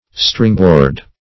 Stringboard \String"board`\ (-b[=o]rd`), n.